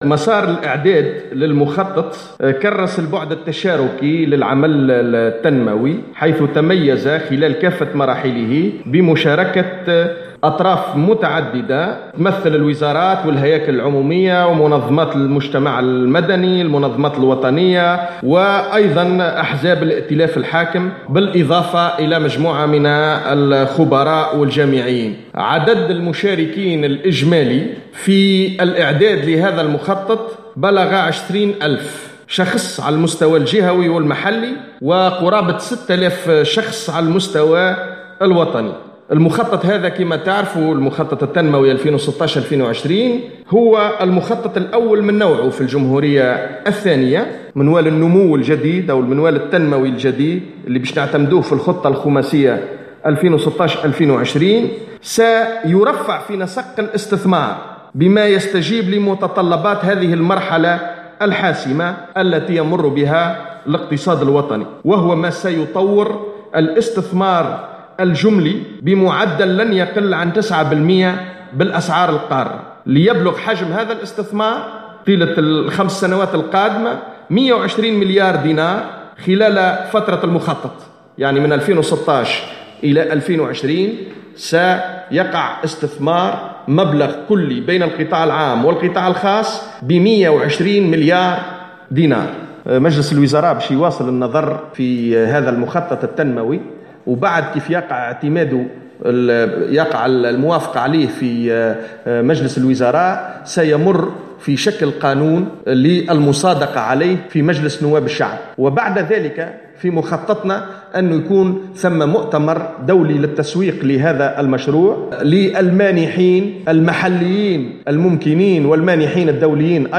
أكدّ الناطق الرسمي باسم الحُكومة خالد شوْكات أنّ 26 ألف شخص ساهموا في الإعداد لهذا المخطط على المُستويْين الجهوي و الوطني.